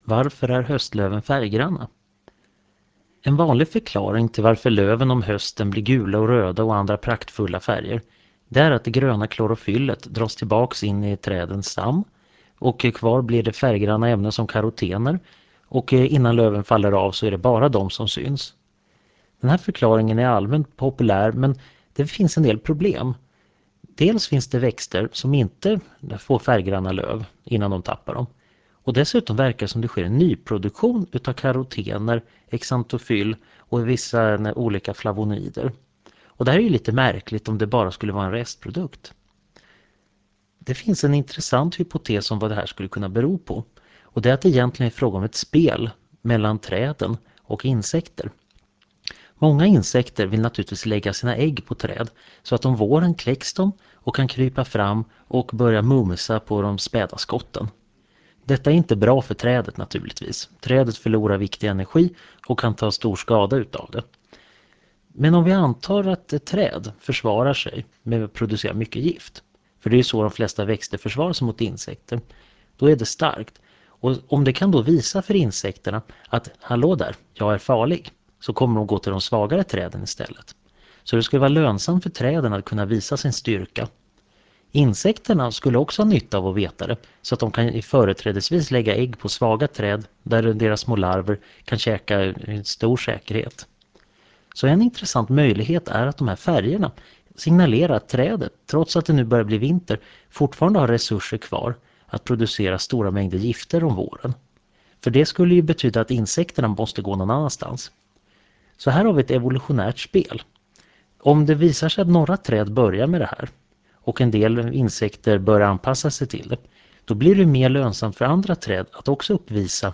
Föredraget handlar om biologi och sänds den 12 november 2000 i Förklarade_Fenomen.